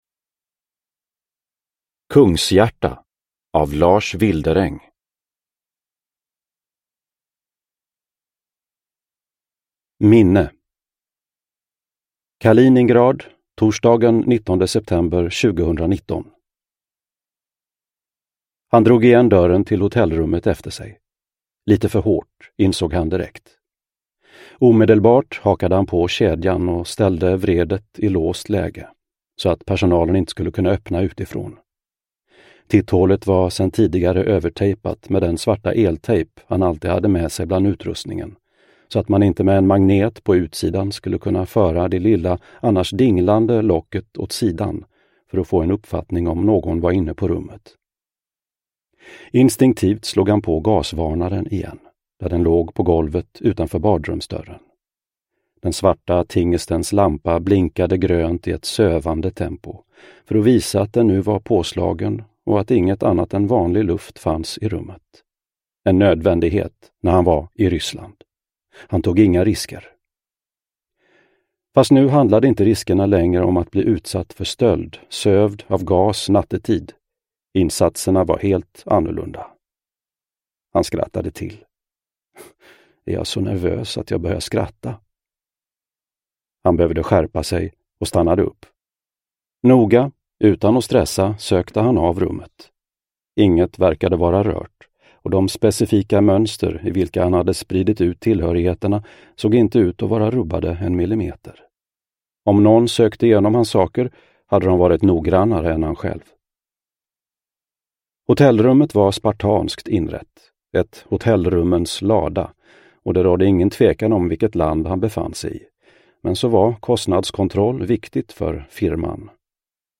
Kungshjärta – Ljudbok – Laddas ner